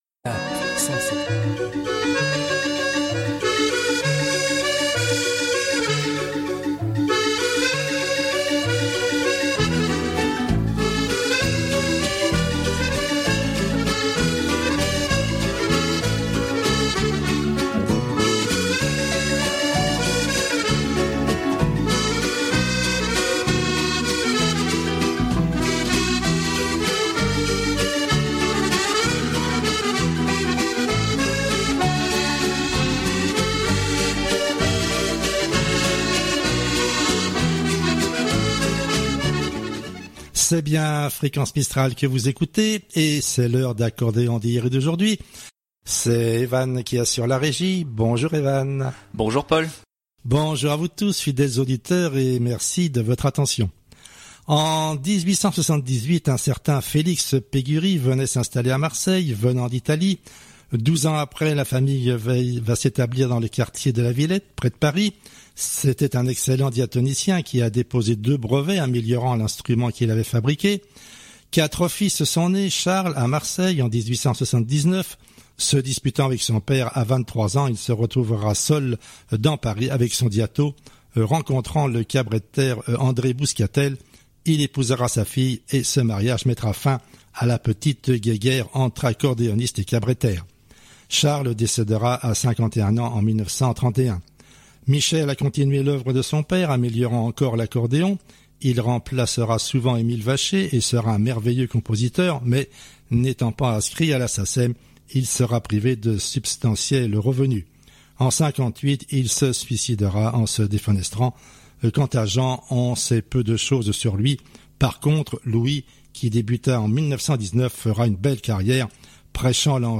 Le programme de l'émission du jour